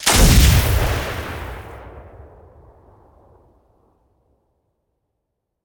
sniper2.ogg